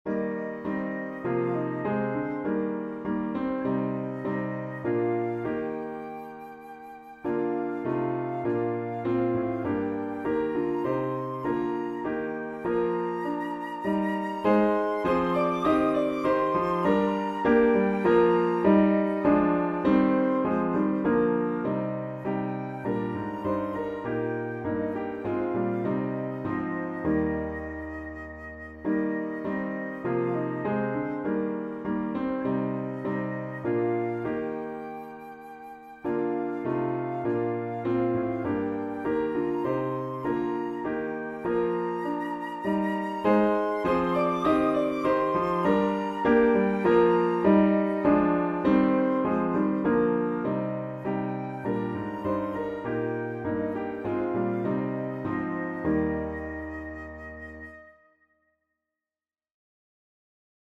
• Easy flute solo with expressive phrasing